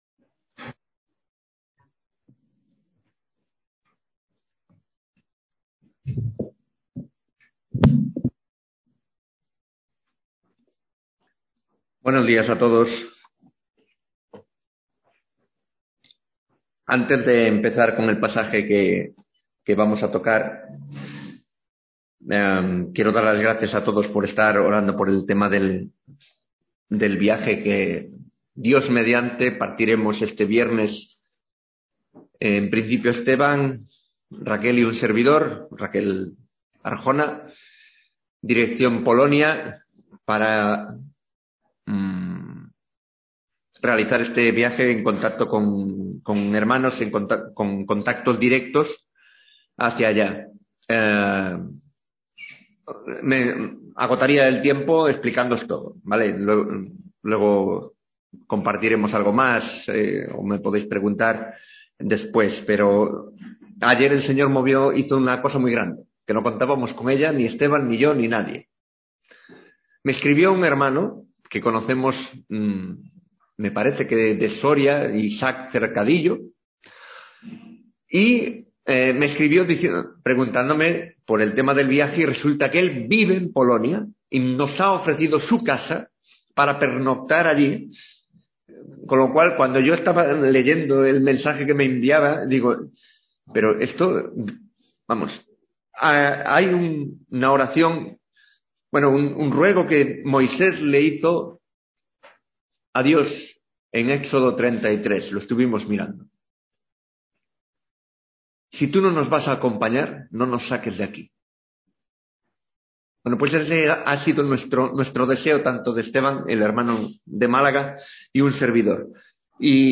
Te invito a escuchar esto que compartí con mis hermanos en la fe.